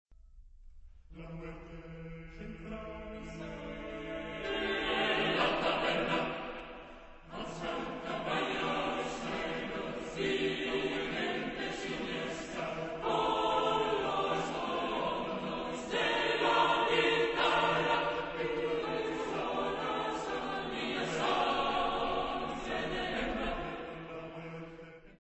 Genre-Style-Form: Secular ; Poem
Mood of the piece: contrasted ; energetic ; dissonant
Type of Choir: SAATB  (5 mixed voices )
Tonality: E tonal center